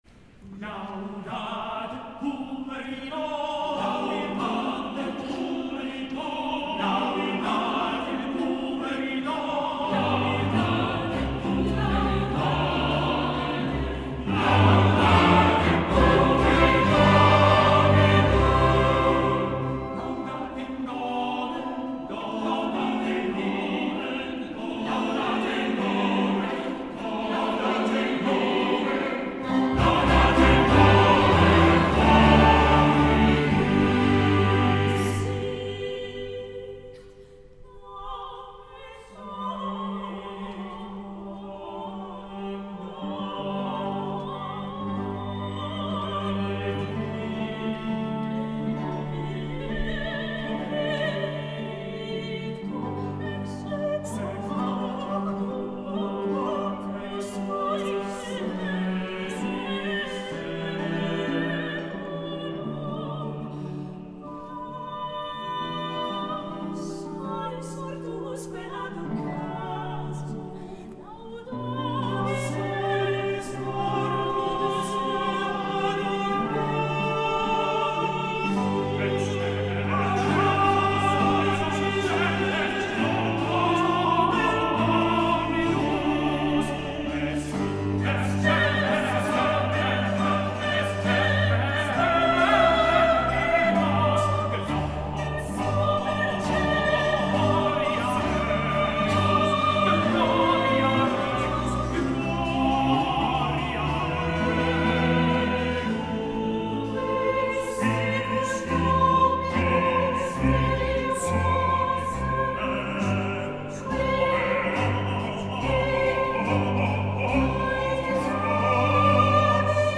The Monteverdi Choir’s 50th Anniversary Concert
Live from King’s College, Cambridge
Boy Choristers from King’s College Chapel, Cambridge
Sir John Eliot Gardiner